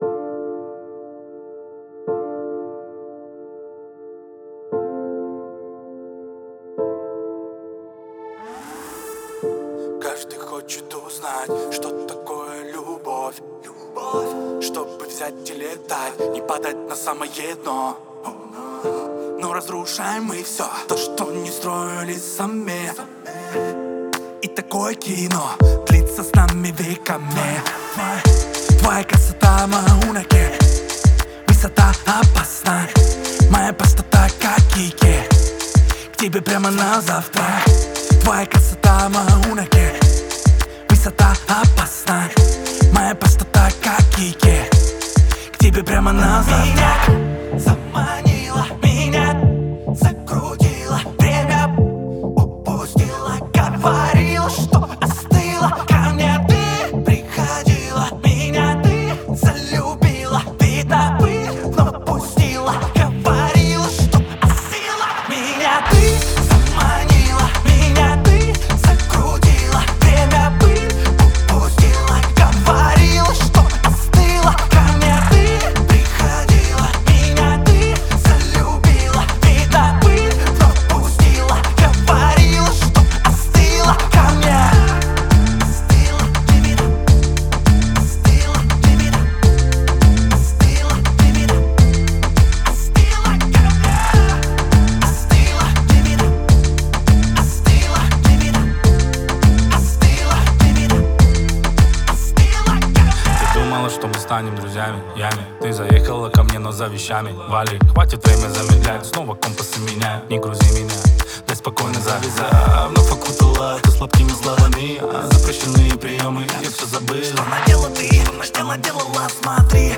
отличается насыщенными гитарами и выразительным вокалом